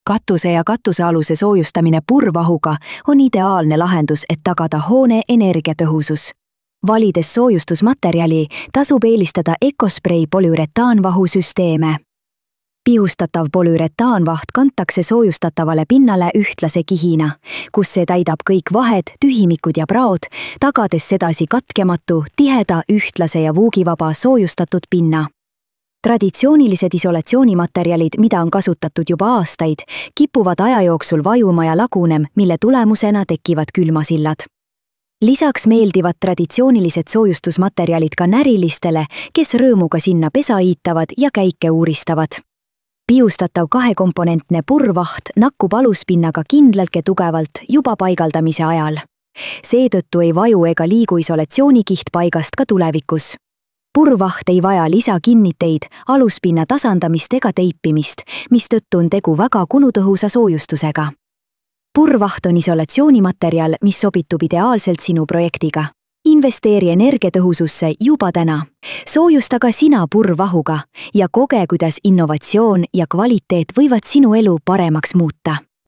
katuse-soojustamine-text-tospeech.mp3